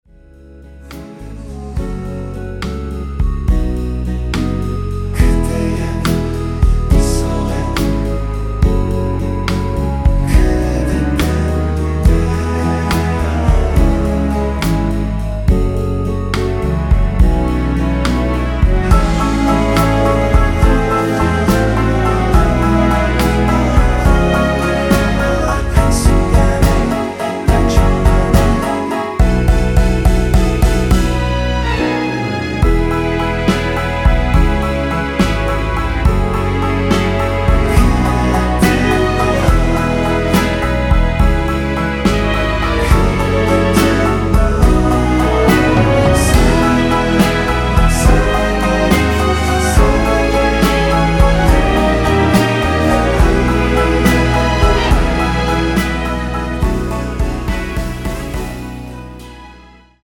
원키에서(-2)내린 멜로디와 코러스 포함된 MR입니다.(미리듣기 확인)
앞부분30초, 뒷부분30초씩 편집해서 올려 드리고 있습니다.